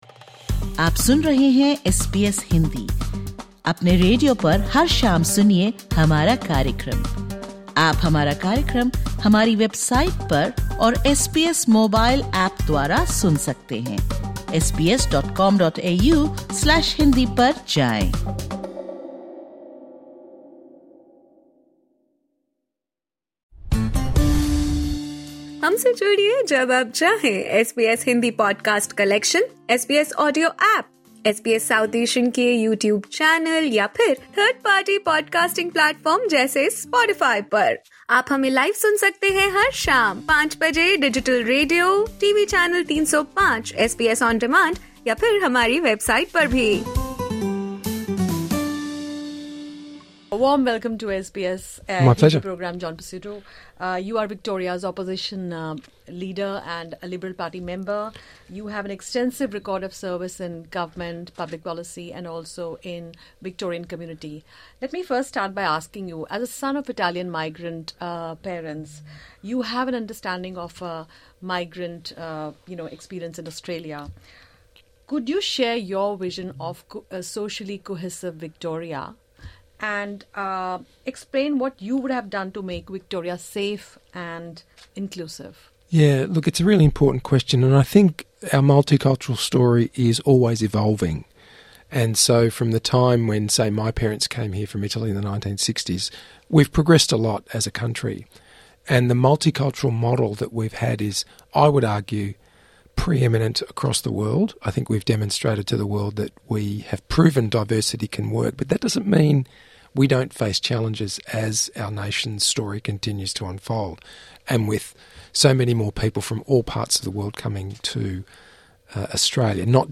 Victorian Opposition Leader John Pesutto recently visited the SBS studio In Melbourne. Listen to this podcast for his exclusive interview with SBS Hindi, where he discusses a range of issues, including the representation of candidates of Indian descent in the next state elections, his vision for making Victoria safe and inclusive, state's recent strategy to engage with and improve trade relations with India, and his action plan for international students.